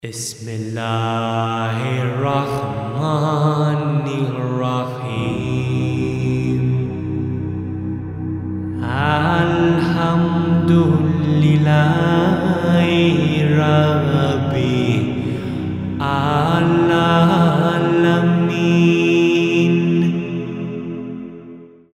Anonymous composers of Quranic chant (7th-12th cent. AD) Surah Fatiha.